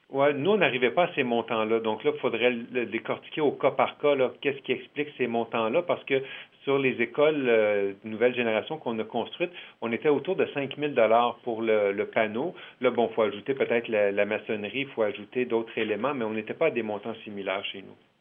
En entrevue au service de nouvelles de M105